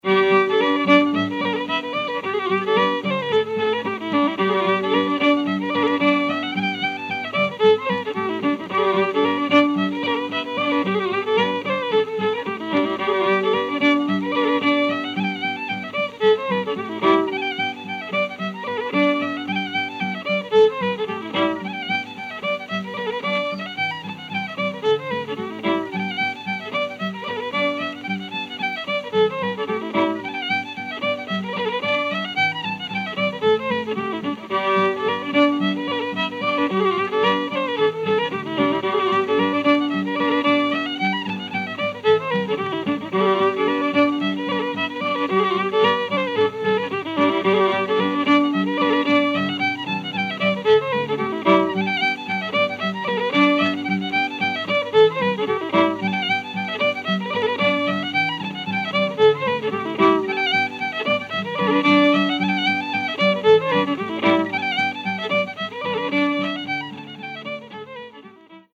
The Longford Collector - Irish Reel